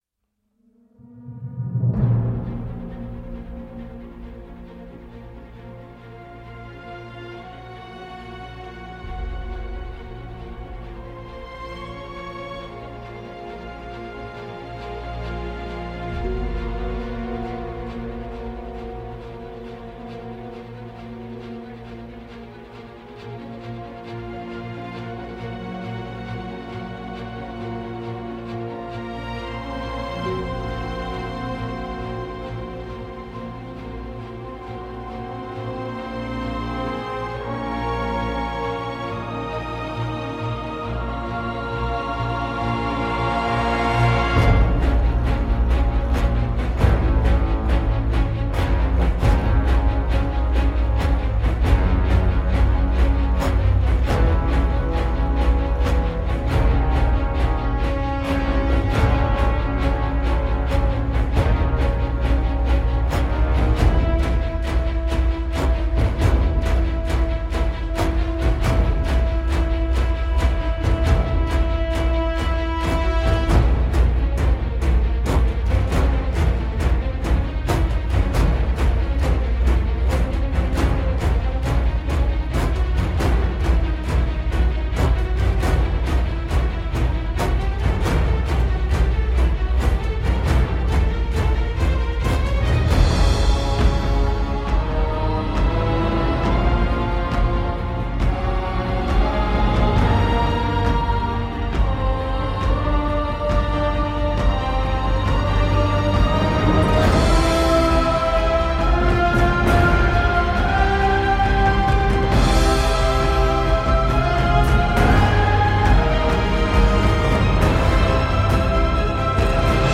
Du remplissage fade.